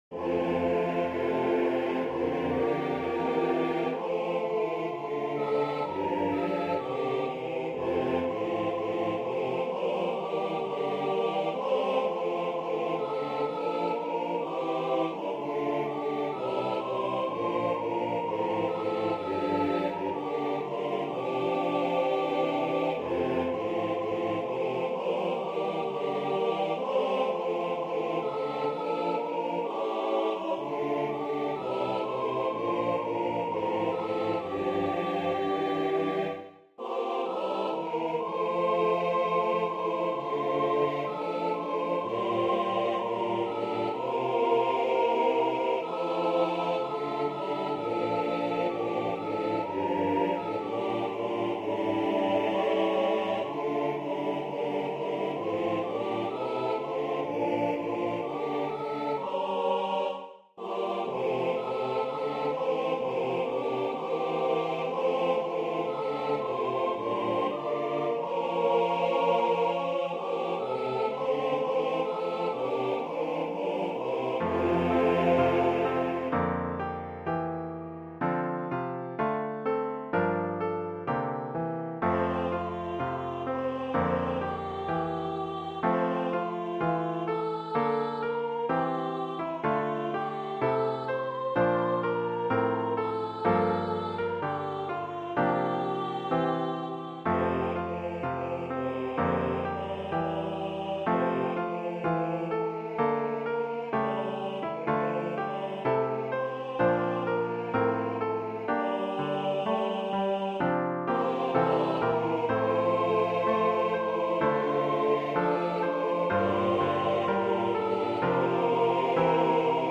SATB Choir Arrangement
Voicing/Instrumentation: SATB